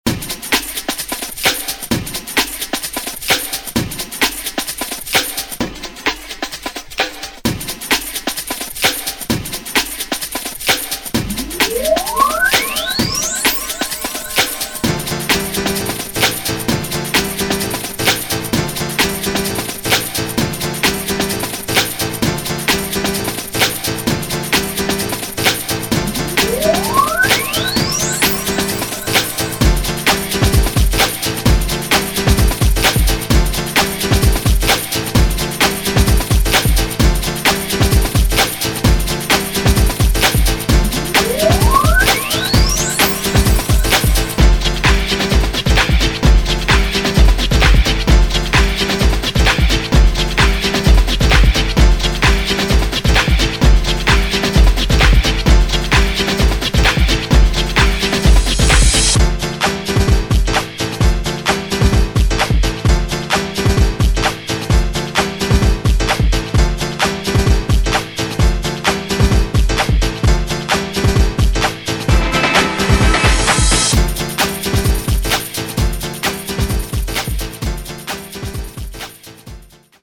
Alabama's interpretation of Baltimore Club music